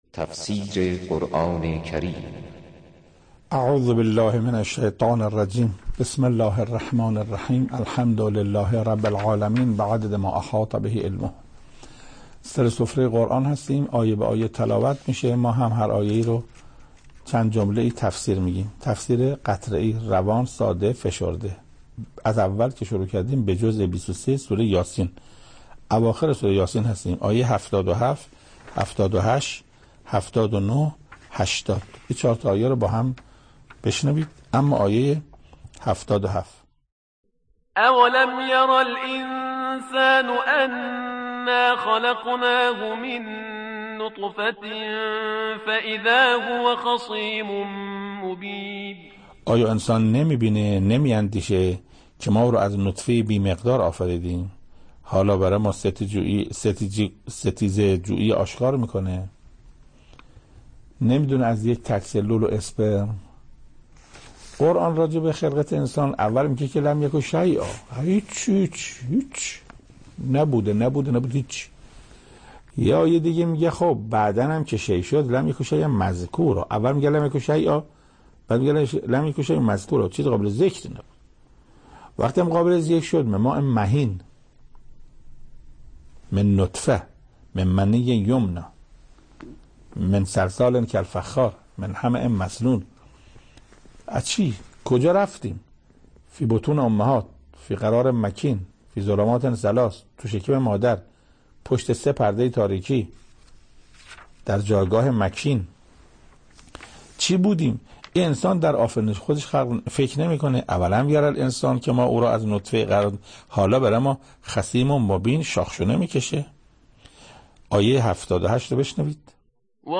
تفسیر صوتی سوره یس